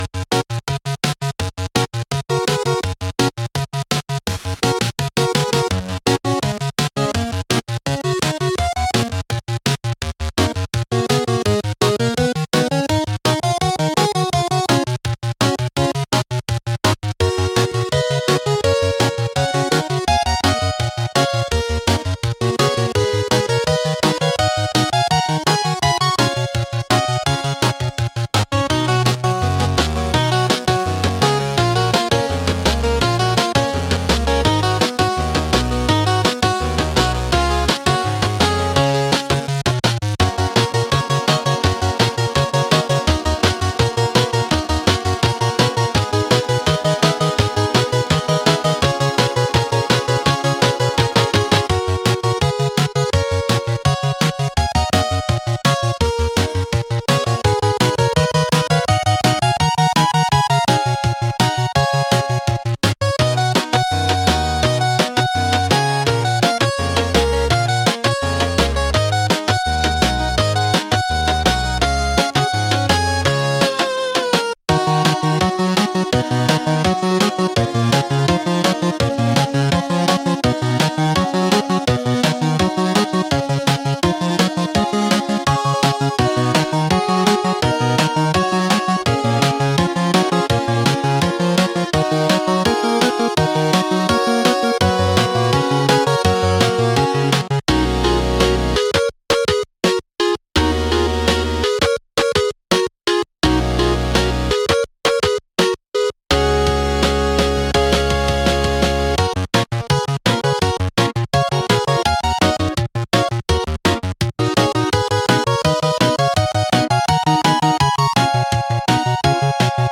フリーBGM 🎶 ピコピコと跳ねる8bitサウンドに、ちょっぴり切ない冒険心を乗せて。